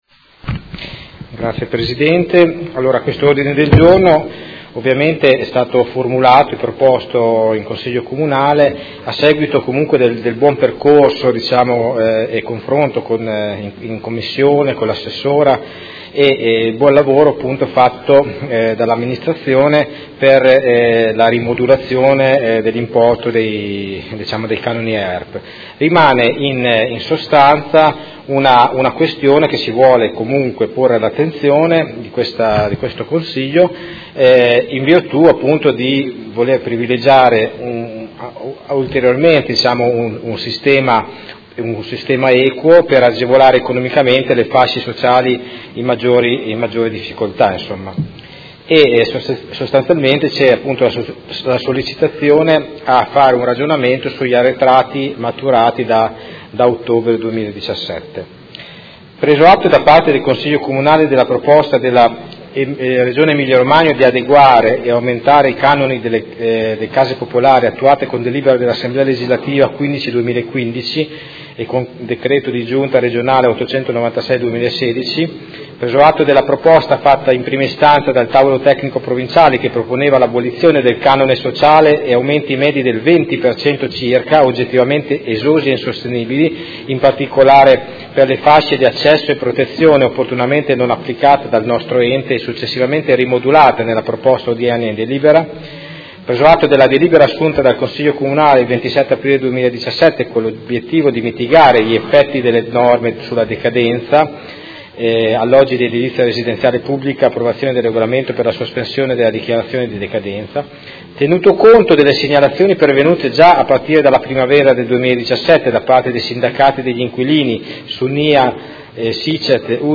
Vincenzo Walter Stella — Sito Audio Consiglio Comunale
Seduta del 5/04/2018. Presenta Ordine del Giorno collegato a proposta di deliberazione: Approvazione Regolamento per la definizione delle modalità di calcolo e di applicazione dei canoni di locazione degli alloggi di edilizia residenziale pubblica con decorrenza 1 ottobre 2017